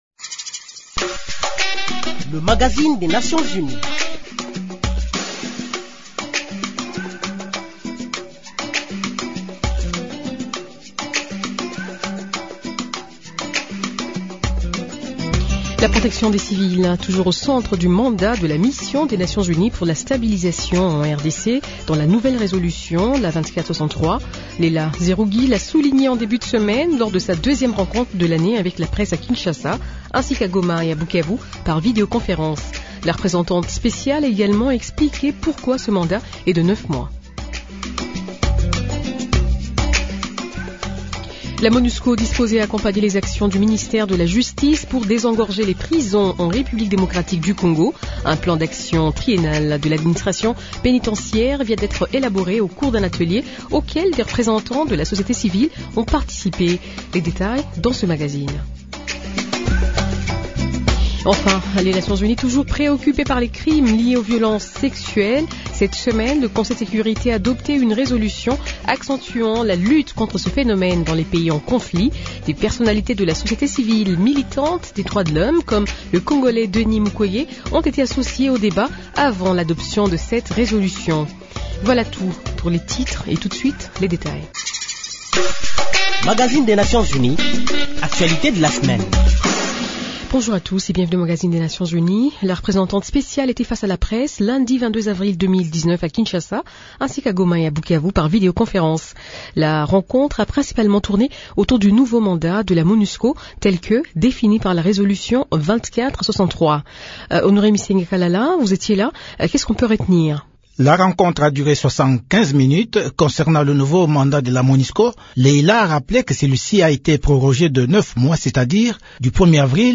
La RDC a-t-elle les moyens d’atteindre cet objectif à cette échéance ? Deux experts du Programme des Nations unies pour le développement (PNUD/RDC) font le point de cet objectif.